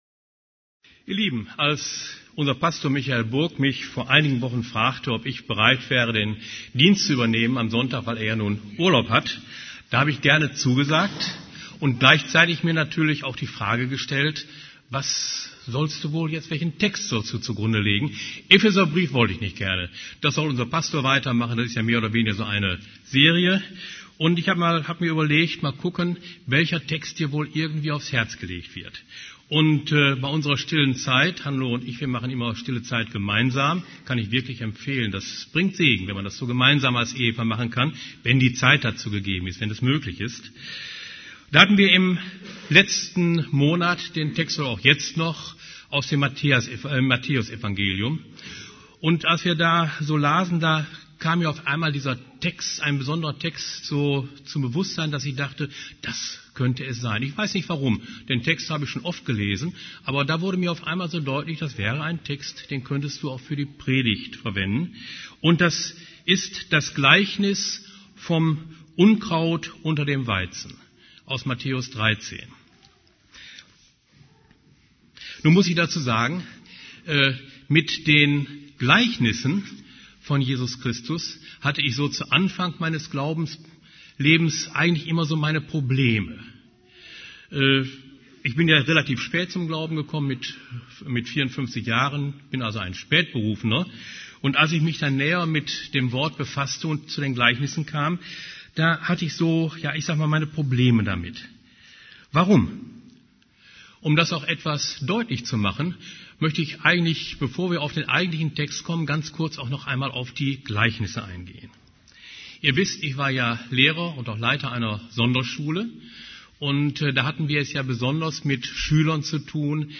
> Übersicht Predigten Das Gleichnis vom Unkraut unter dem Weizen Predigt vom 16.